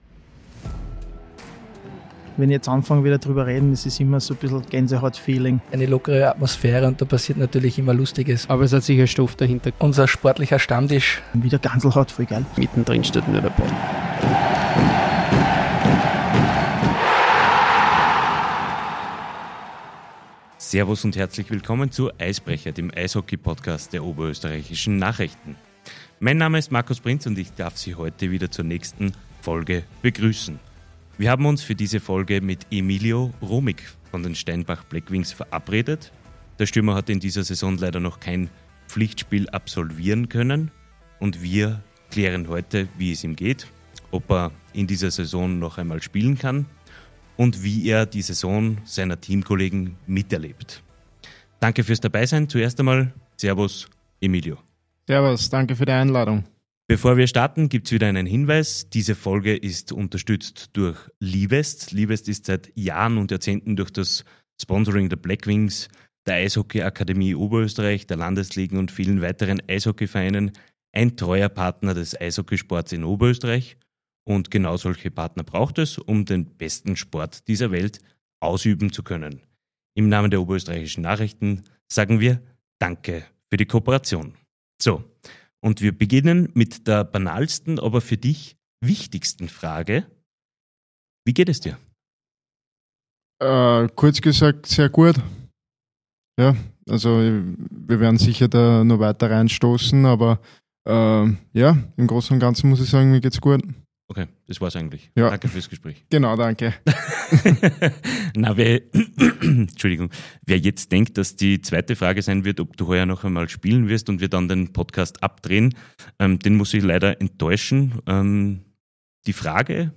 Der rekonvaleszente Stürmer im Interview Alle Episoden des Eisbrecher-Podcasts hören Sie auf Der Podcast wird Ihnen präsentiert von Liwest - weitere Informationen auf Folgen Sie uns in der Podcast-App Ihres Vertrauens: Spotify: Deezer: